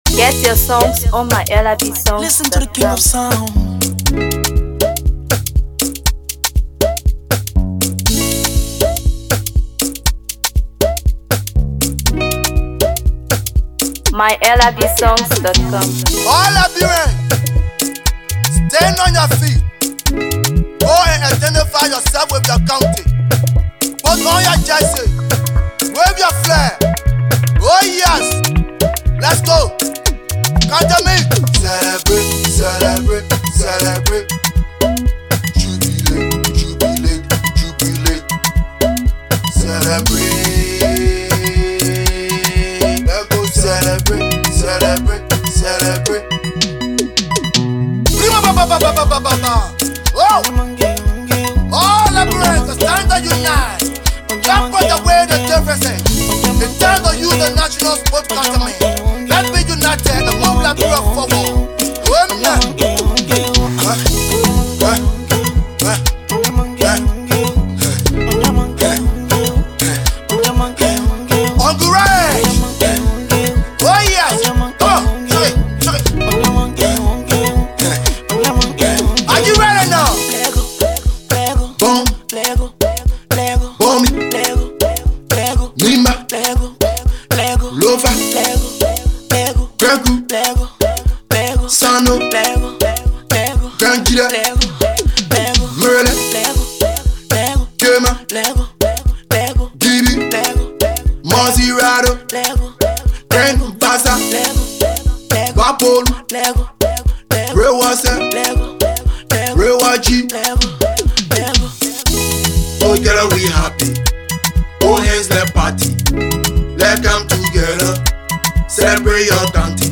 Afro PopMusic